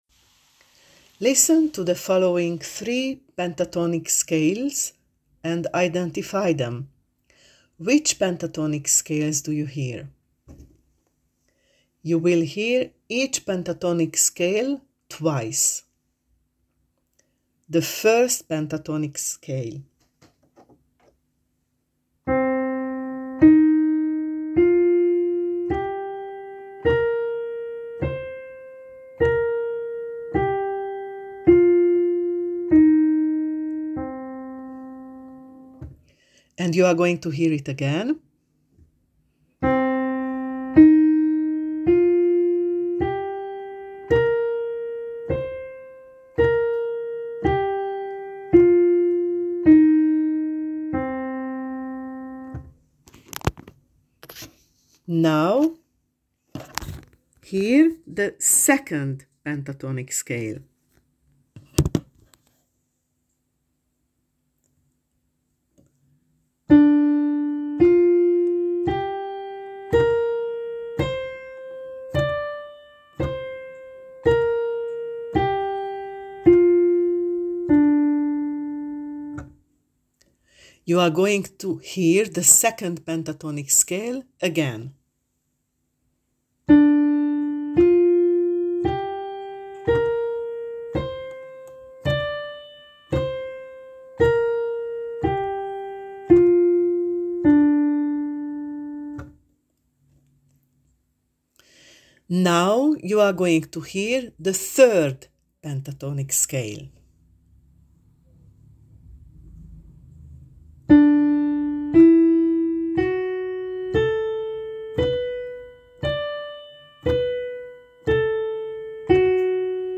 Scale work:
3.Listen to the following 3 pentatonic scales and identify them: Which pentatonic scales do you hear? Example:(do, re, la, etc.):